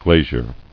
[gla·zier]